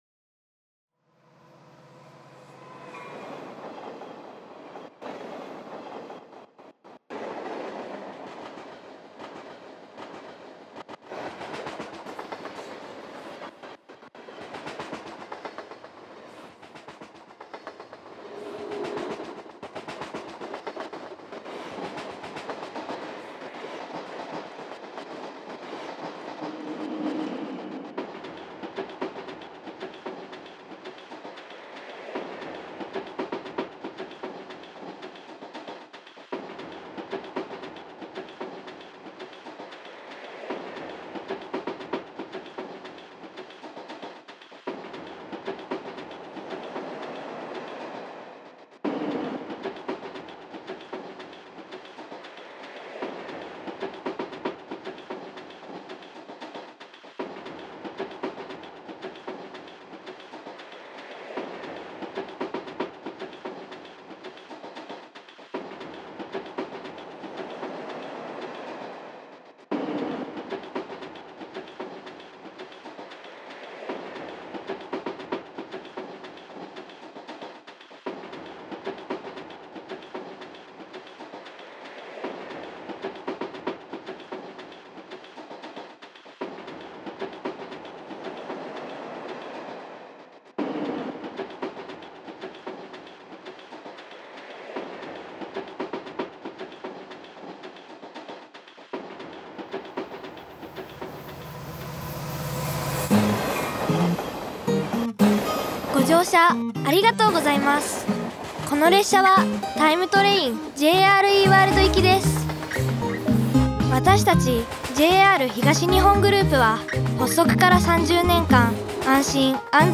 INSTRUMENTAL ELECTRO